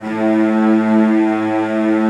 CELLOS BN2-L.wav